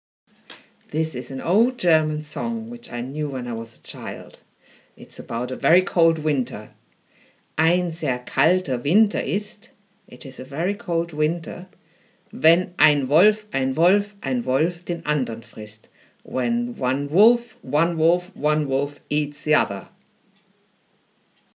A new German round